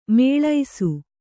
♪ mēḷaisu